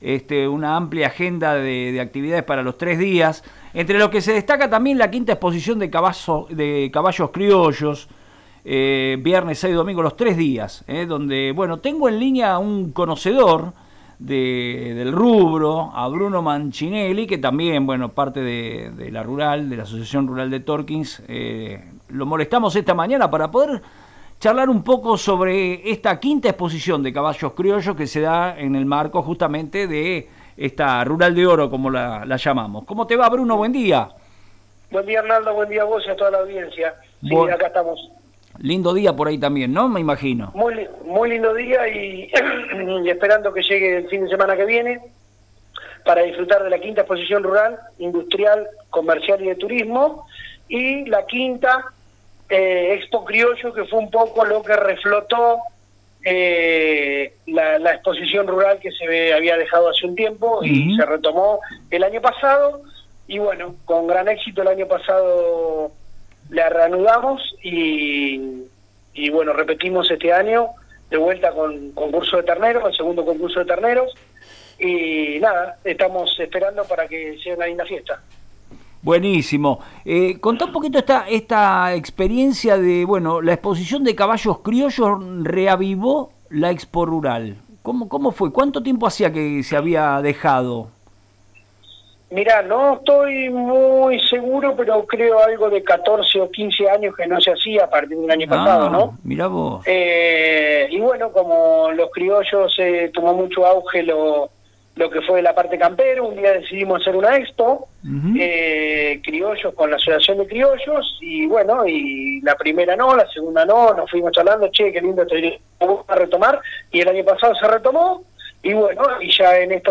En diálogo con el programa Un mate y un café por FM Reflejos